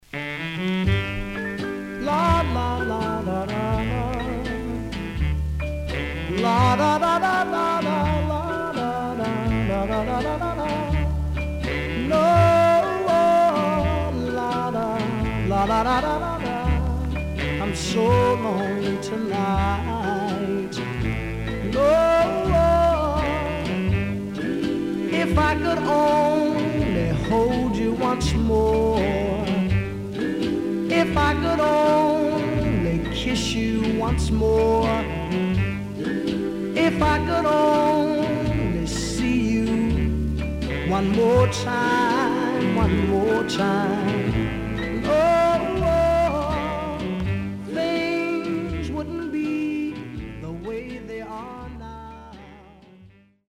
SIDE A:少しチリノイズ入ります。